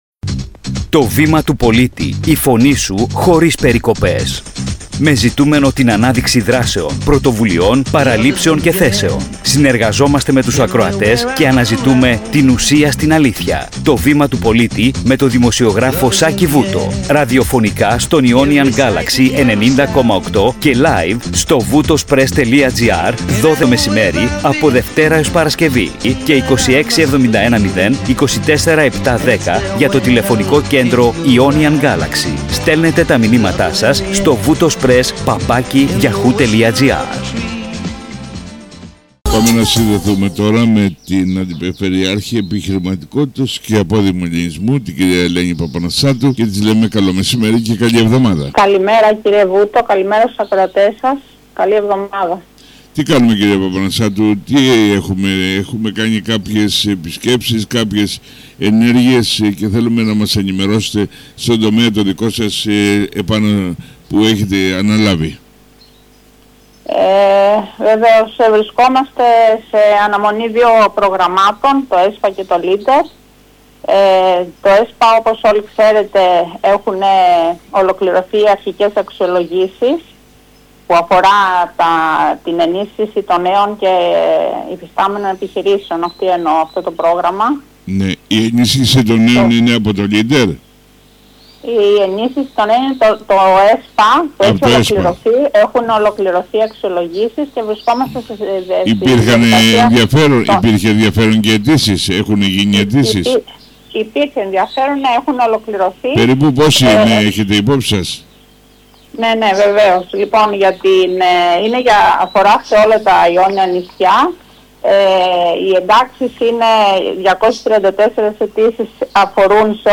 φιλοξενήθηκε η Αντιπεριφερειάρχης Επιχειρηματικότητας και Απόδημου Ελληνισμού, κα Ελένη Παπαναστασάτου. Η συζήτηση επικεντρώθηκε στα τρέχοντα και επερχόμενα προγράμματα ΕΣΠΑ και Λίντερ, στην ενίσχυση των μικρών και οικογενειακών επιχειρήσεων, καθώς και στη σχέση της Περιφέρειας με τον απόδημο ελληνισμό.